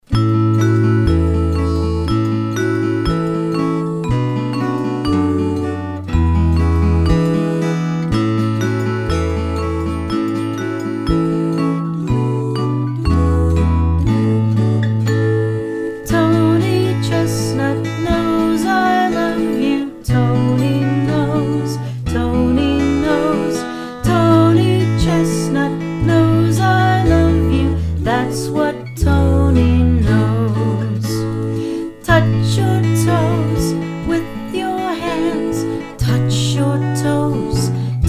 Children's Song Lyrics and Sound Clip
Folk Music Lyrics